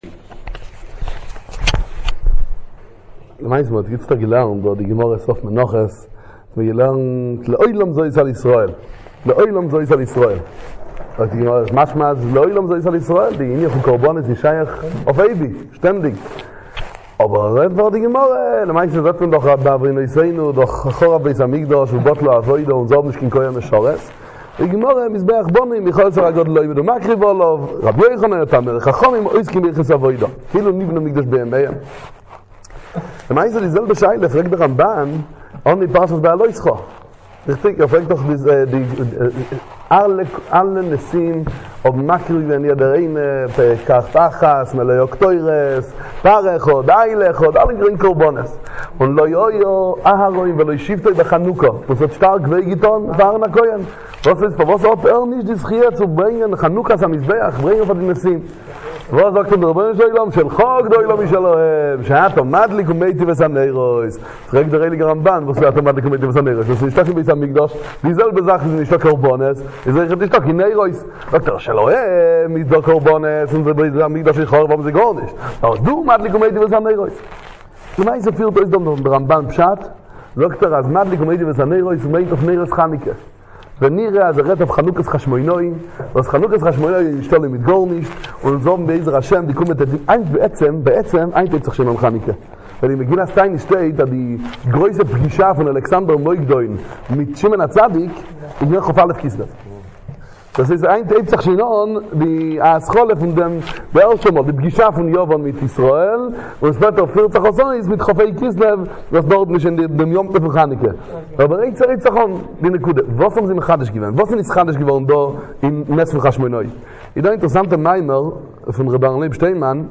לרצונכם תזבחו - סיום מנחות בקלויז דחסידי באיאן